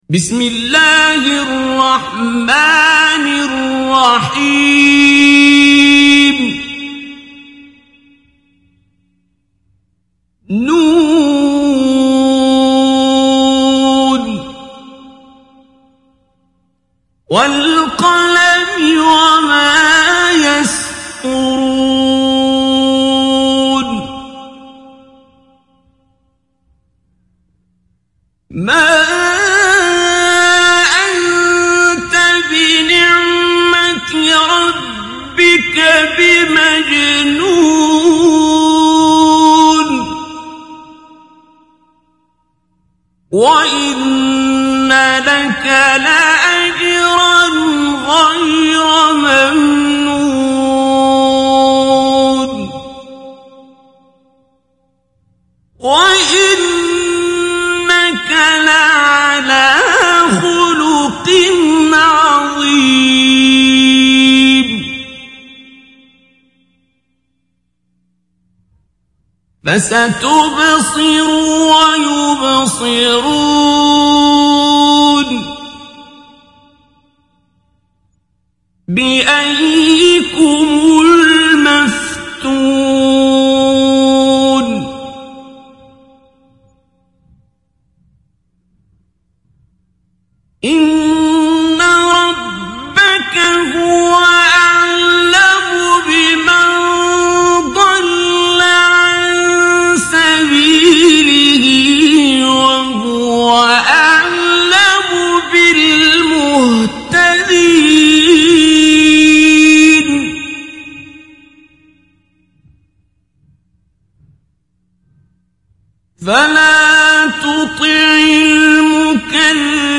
Қуръони карим тиловати, Қорилар. Суралар Qur’oni karim tilovati, Qorilar. Suralar